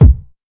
• 2000s Boom Bass Drum Single Shot E Key 40.wav
Royality free bass drum one shot tuned to the E note. Loudest frequency: 123Hz